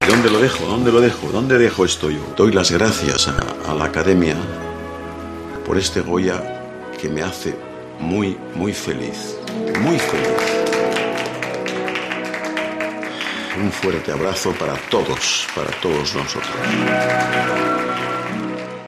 Antonio Mercero, al recibir el Goya de Honor en 2010: “Me hace muy, muy feliz”